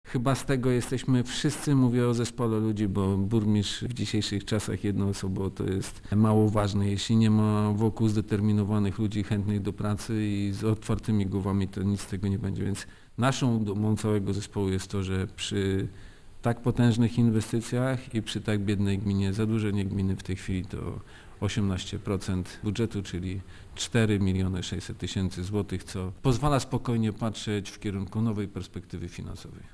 Andrzej Ćwiek podkreśla, że realizacja tak wielu inwestycji, nie spowodowała nadmiernego zadłużenia gminy, które wynosi 4,6 mln złotych co stanowi 18 procent: